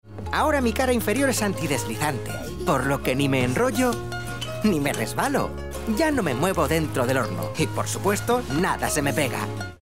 Comercial, Natural, Versátil, Seguro, Empresarial